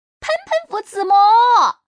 Index of /hunan_feature2/update/1271/res/sfx/changsha_woman/